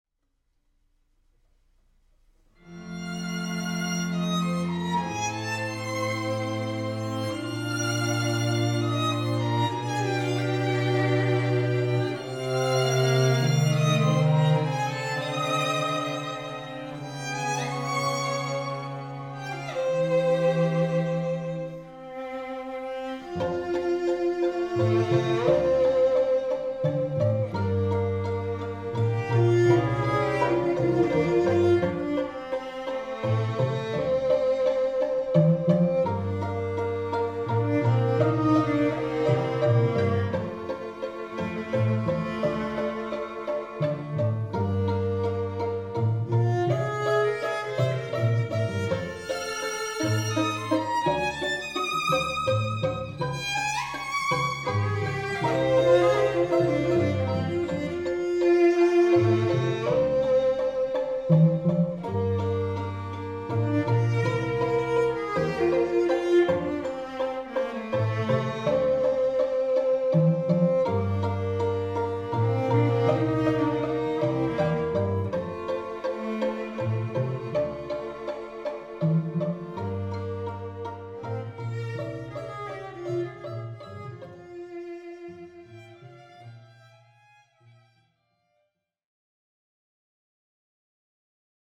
quartet arrangement